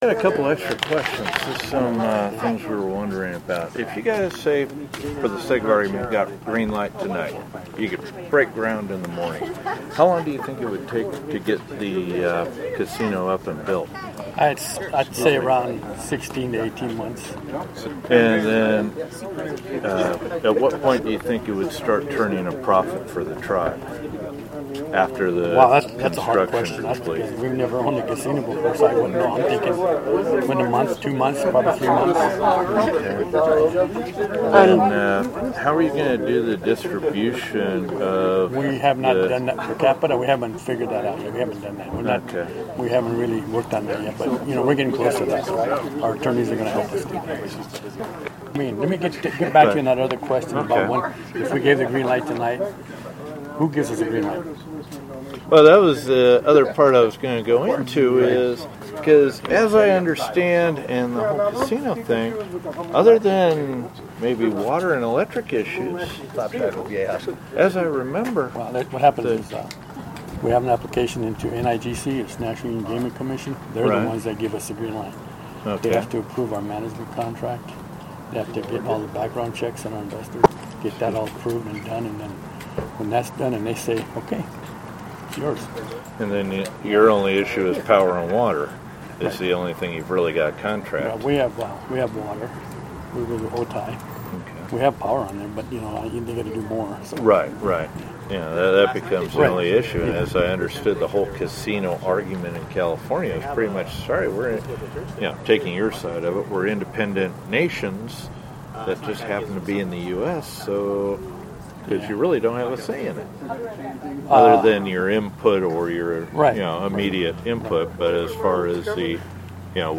Jamul Tribal Chairman Raymond Hunter, Sr.  spoke with media before the meeting.